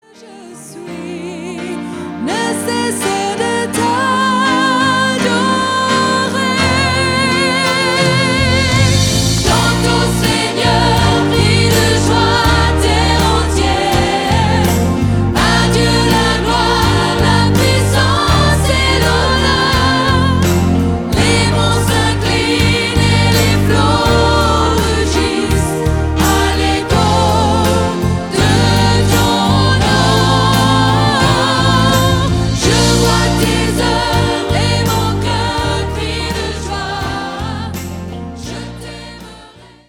des chants dynamiques enregistrés en public.